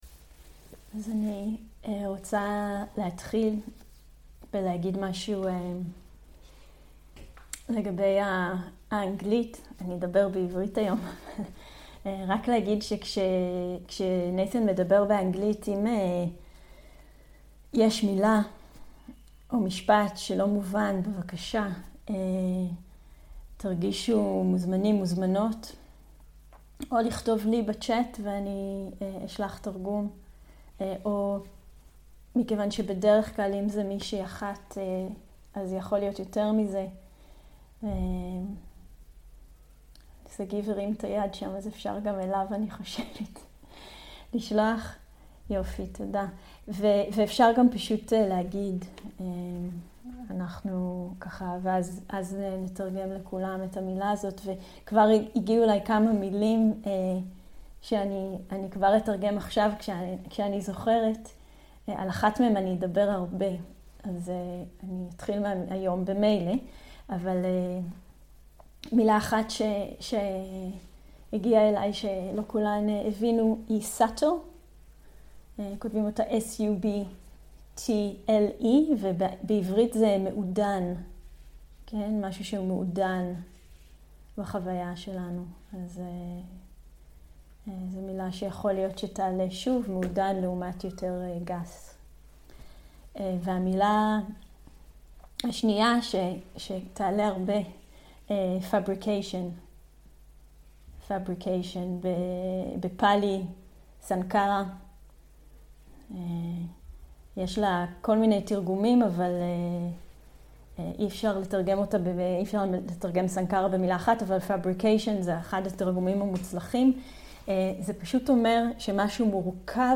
שיחת דהרמה - מהי מטא
סוג ההקלטה: שיחות דהרמה
איכות ההקלטה: איכות גבוהה